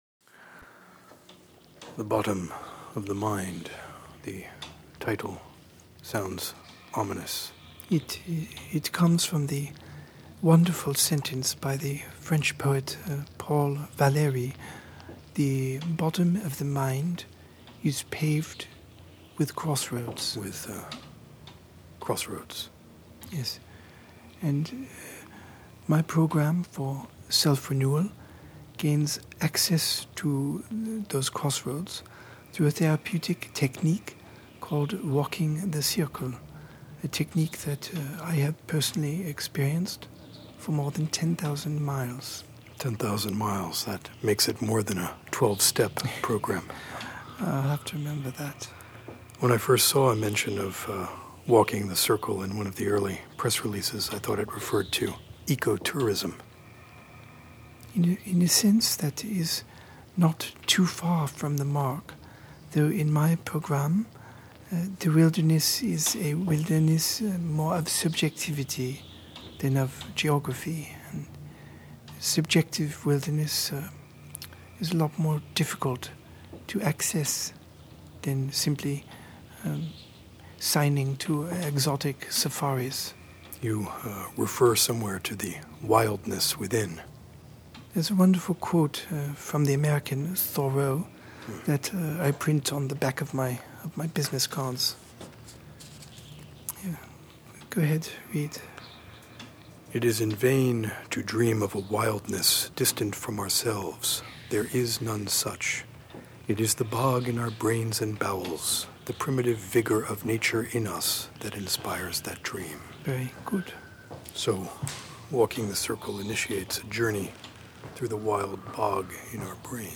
A conversation between a Host and a conjured revolutionary cyclotherapist Guest.